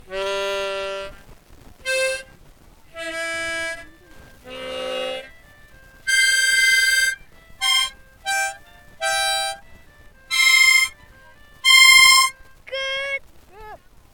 하모니카.mp3